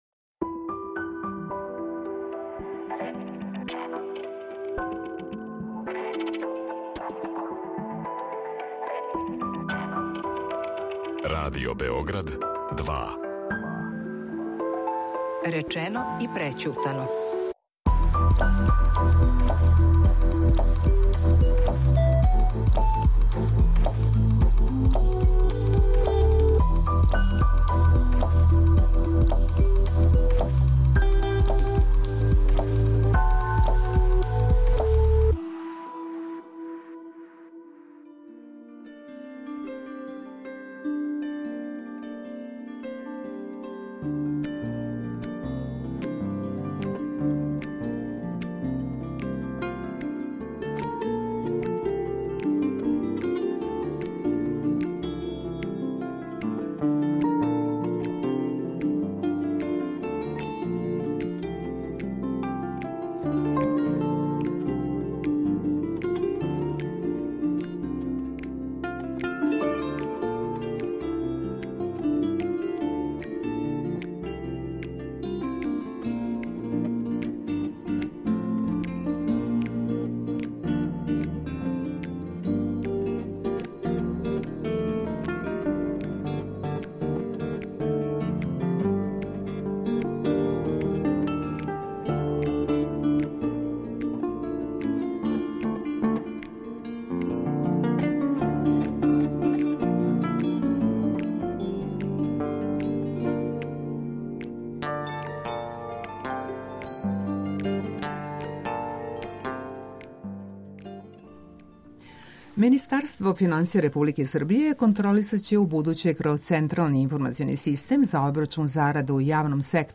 Питамо поред осталог: Сашу Стевановића, државног секретара у Министарству финансија